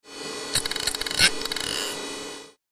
... из корпуса издаются инородно рожденные звуки. Не громко, но настойчиво, не быстро, но мгновенно пугающе.
Попадаемый в ушные раковины звук из системника чем-то напоминал падающие шарики. Знаете, такие маленькие, из небольших подшипников.
Спустя полтора года были записаны те самые звуки...